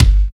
51 KICK 3.wav